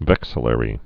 (vĕksə-lĕrē)